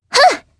Sonia-Vox_Attack1_jp_b.wav